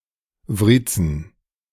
Wriezen (German pronunciation: [ˈvʁiːt͡sn̩]